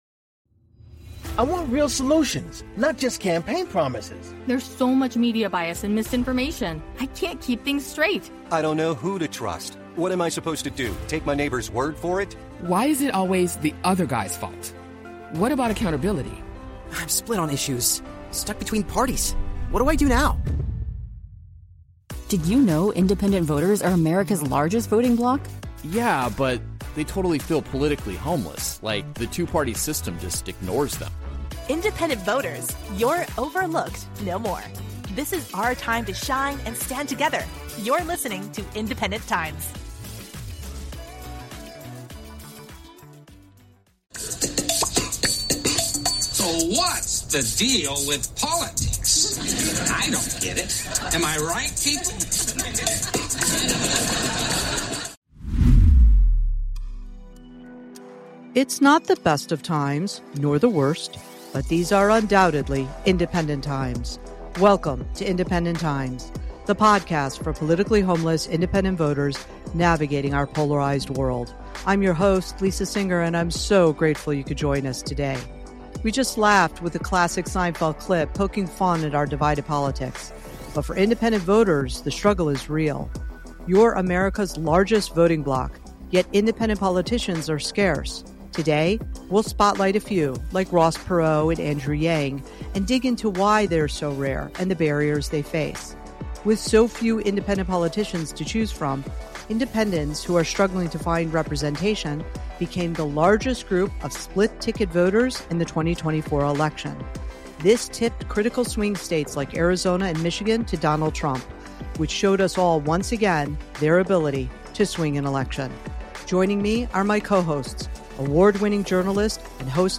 08:25 Angus King explains that most Maine voters are unenrolled (clip)
10:05 Andrew Yang makes the case for universal basic income (clip)